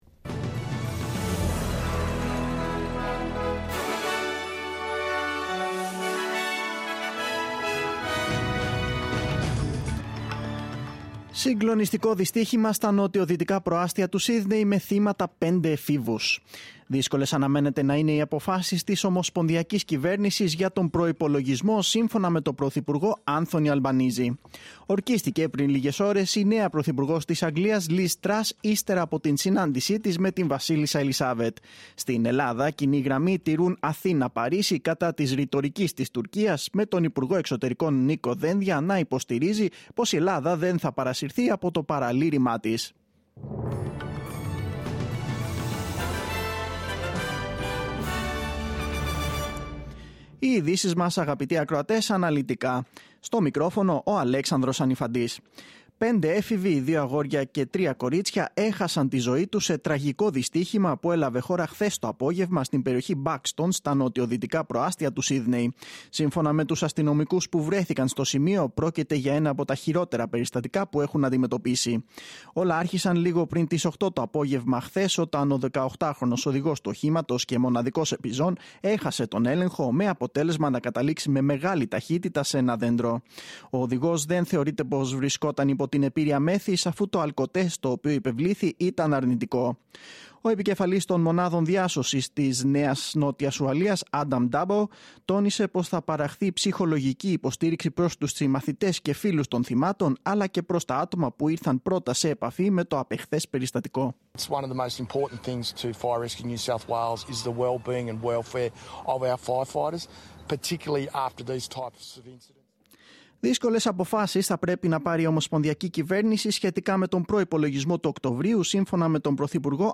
Δελτίο Ειδήσεων: Τετάρτη 7-9-2022
δελτιο-ειδησεων-7-σεπτεμβριου.mp3